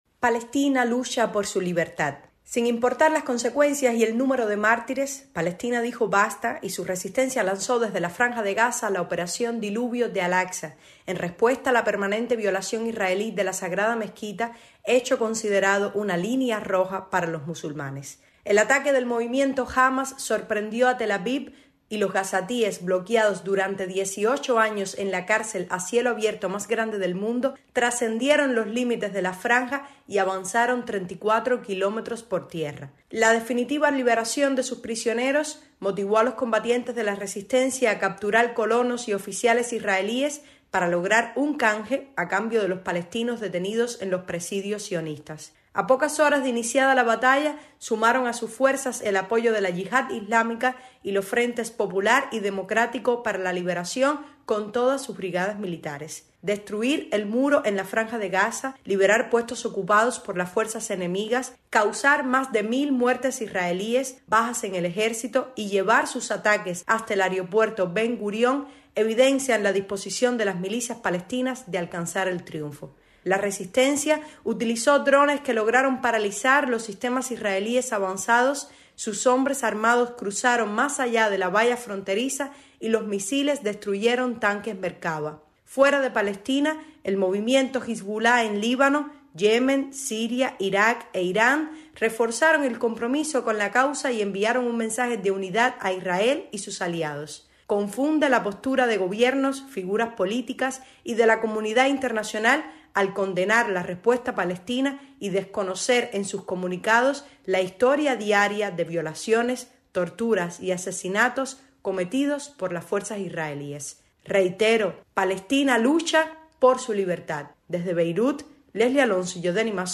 desde Beirut